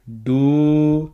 muharni - A Clojure library designed to hack up a web page for studying the Punjabi script and the pronunciation thereof approved for the reading of Sikh sacred texts.